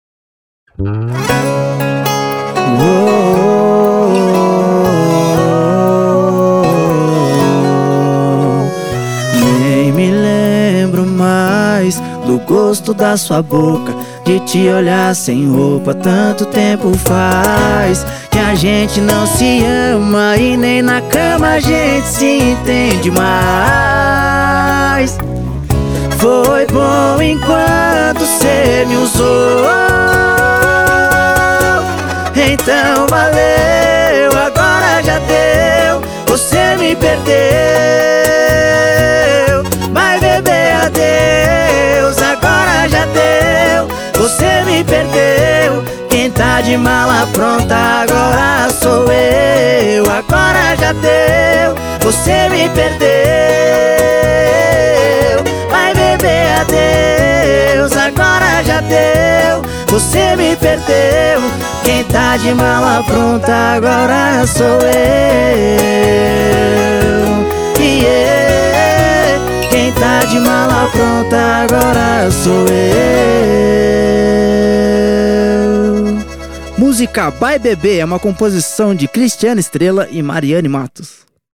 Sertanejo Romântico